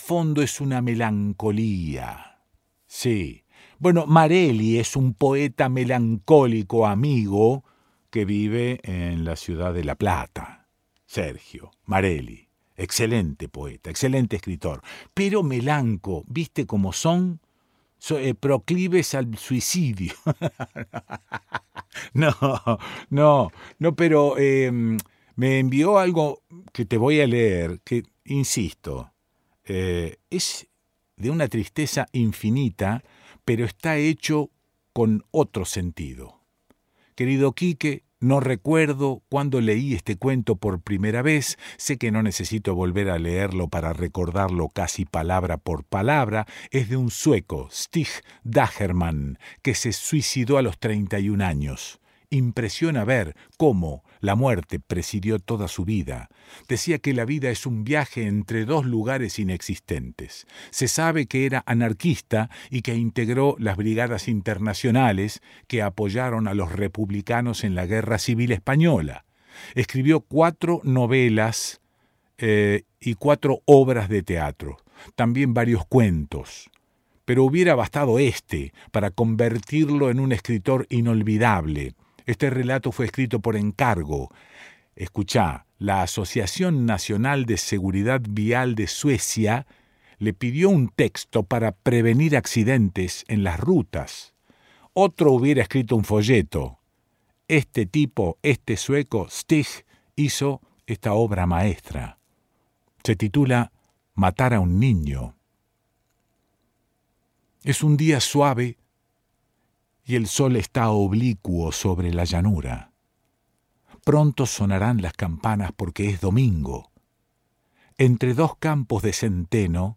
Leído por el conductor del programa: Quique Pesoa.
Además de ser estupendamente leído, resulta conmovedor por lo que se cuenta, pero también por cómo está escrito.